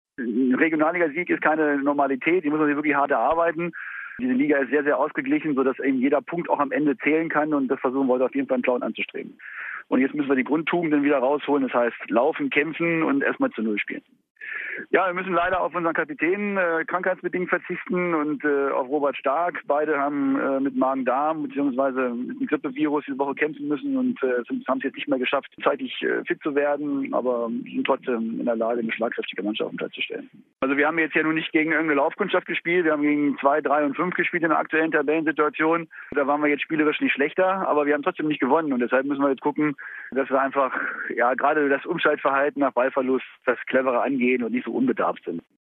im Gespräch mit der Landeswelle